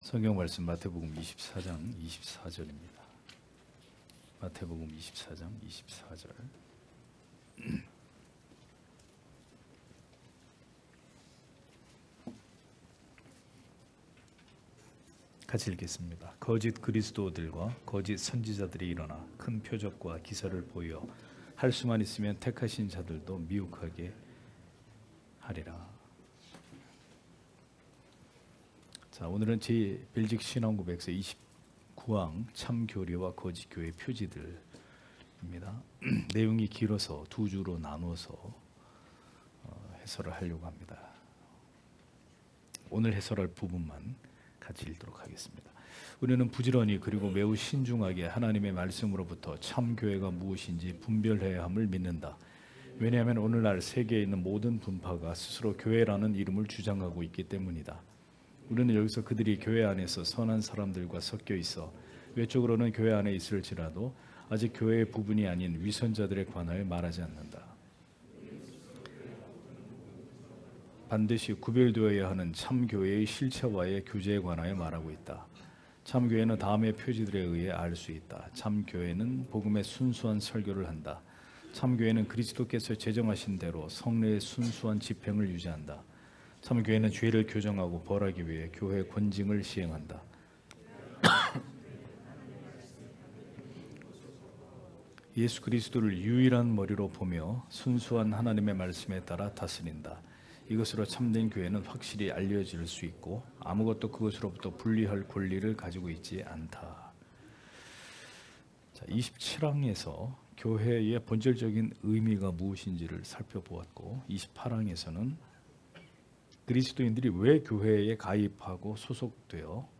* 설교 파일을 다운 받으시려면 아래 설교 제목을 클릭해서 다운 받으시면 됩니다.